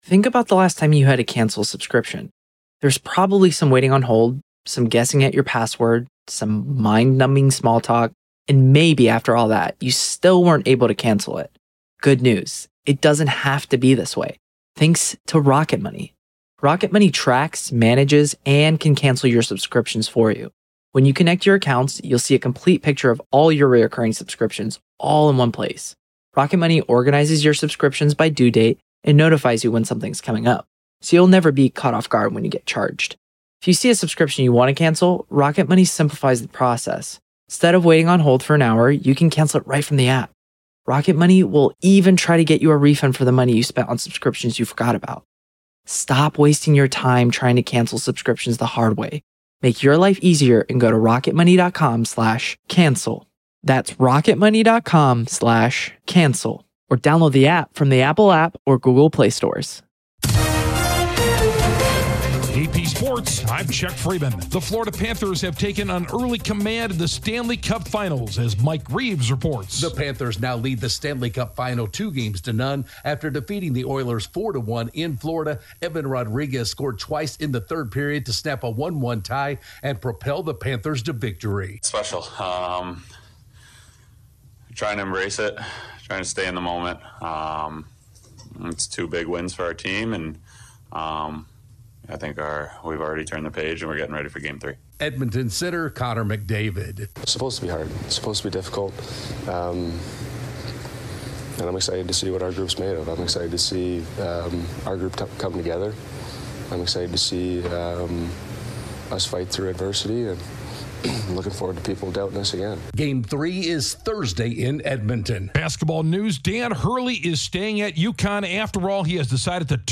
The Florida Panthers take an early charge of the Stanley Cup Finals, Dan Hurley to stay at UConn, Mike Tomlin gets an extention with the Steelers, the Orioles sweep the Rays. Correspondent